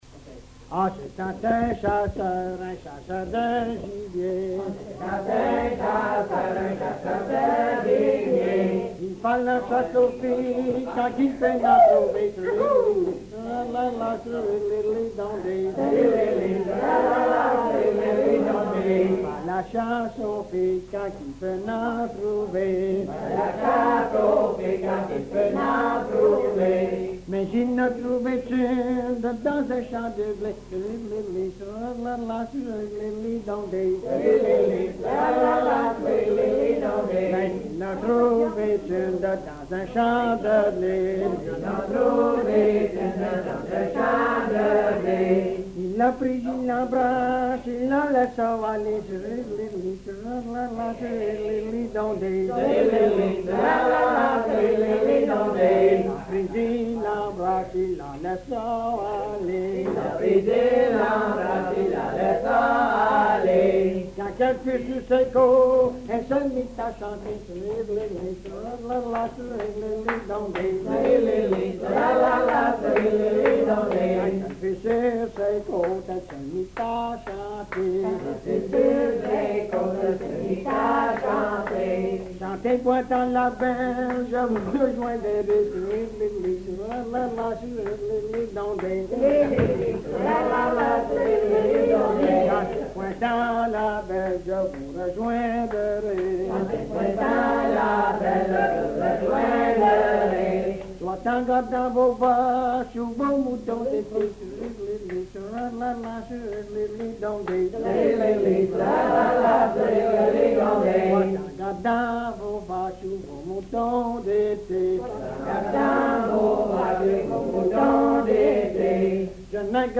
Folk Songs, French--New England